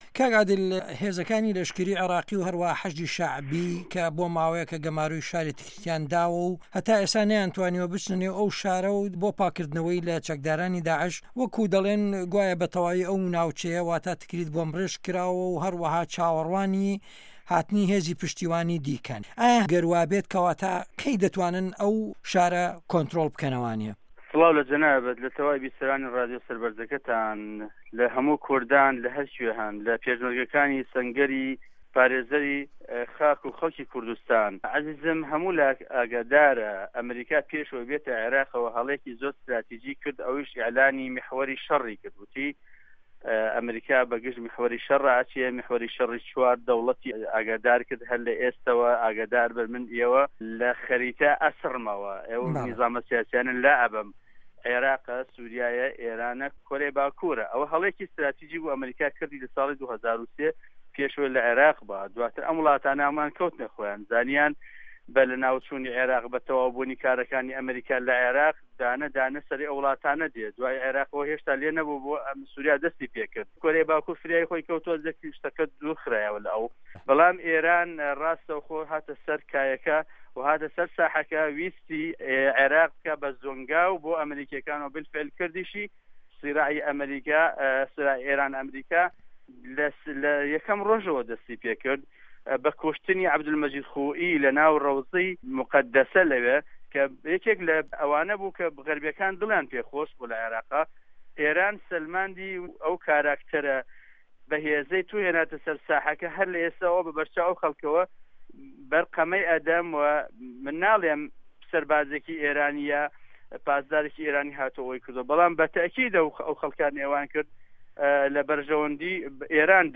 وتوێژی